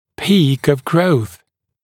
[piːk əv grəuθ][пи:к ов гроус]пик роста